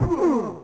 bump.mp3